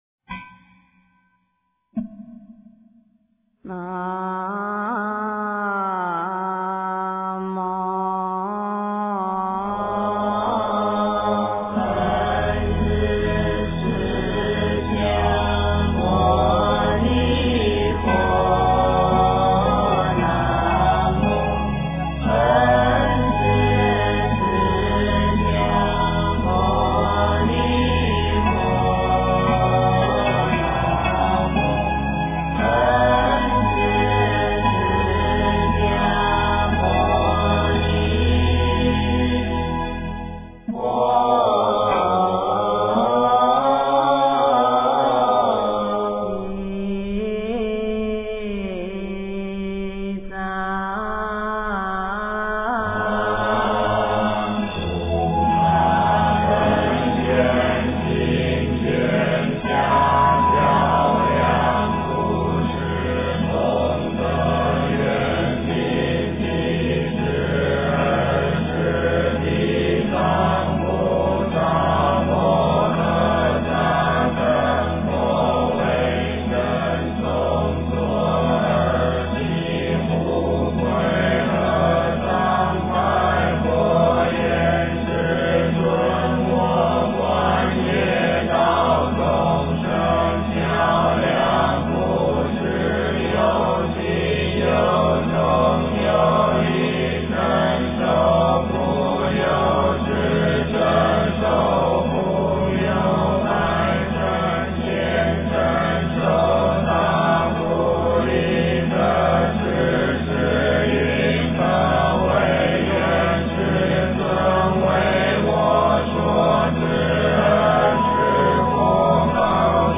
地藏经卷下 诵经 地藏经卷下--如是我闻 点我： 标签: 佛音 诵经 佛教音乐 返回列表 上一篇： 地藏经卷中 下一篇： 达摩祖师四行观 相关文章 观音颂--福音佛乐团 观音颂--福音佛乐团...